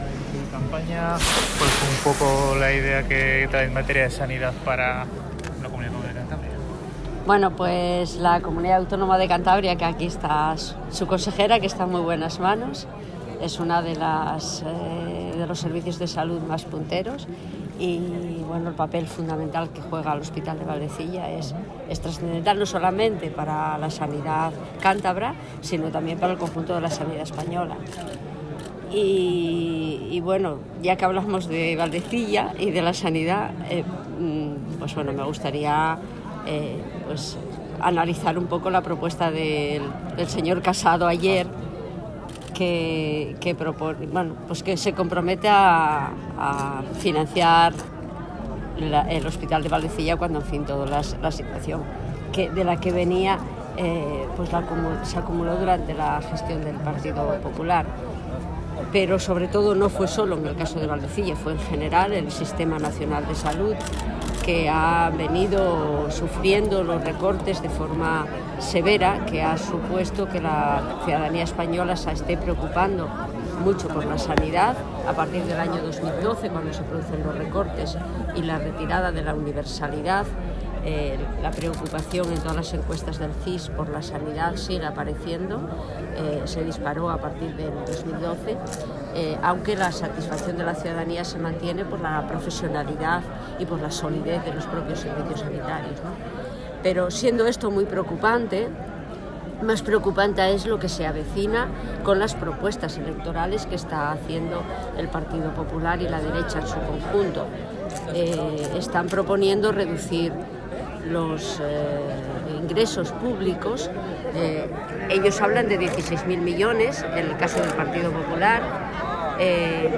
Así lo ha manifestado Zuloaga durante en acto de presentación de candidaturas de la comarca del Asón en Ramales